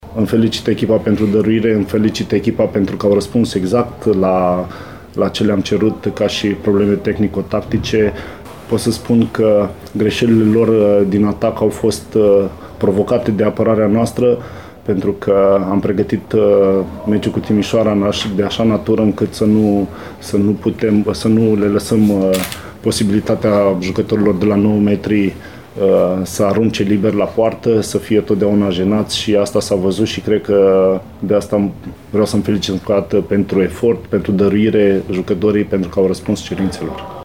Declaraţii după meci: